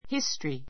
history 中 A1 hístəri ヒ ス トリ 名詞 複 histories hístəriz ヒ ス トリ ズ ❶ 歴史 , （歴）史学; 歴史書 European history European history ヨーロッパ史, 西洋史 This is a place (which is) famous in history.